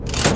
Lever5.wav